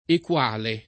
equale [ ek U# le ]